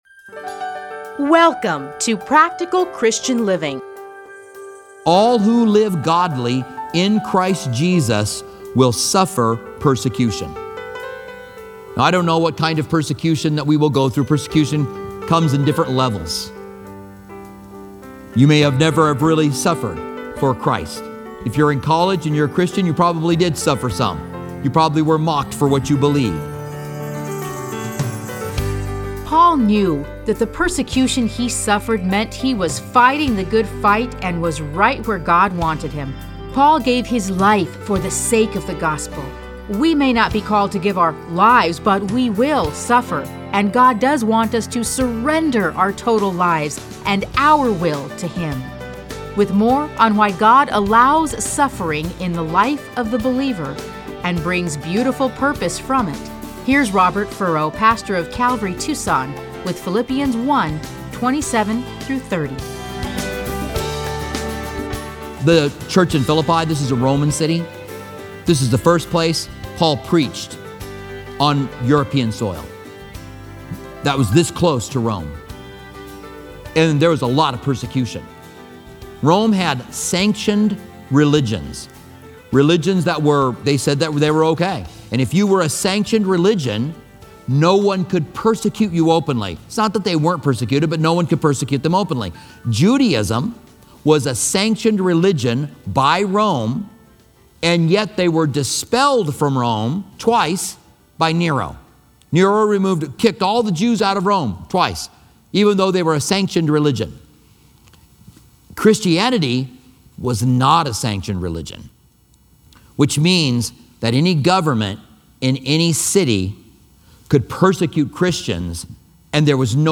Listen to a teaching from A Study in Philippians 1:27-30.